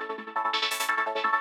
SaS_MovingPad04_170-A.wav